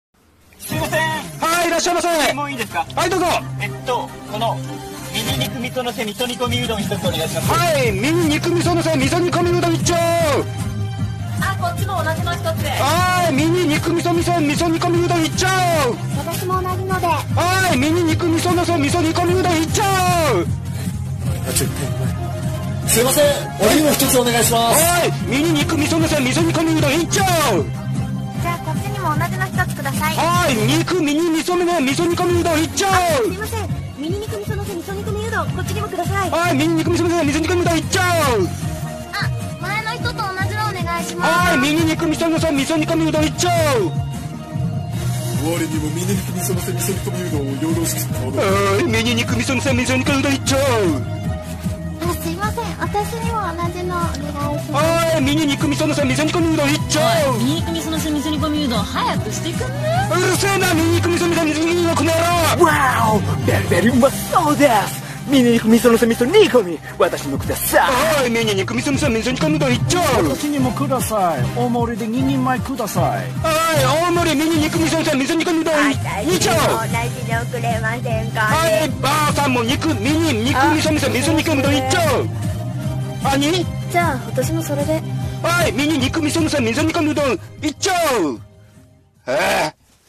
【大繁盛うどん屋声劇声面接】